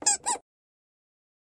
Baby Toy Squeeze Toy, Single Squeeze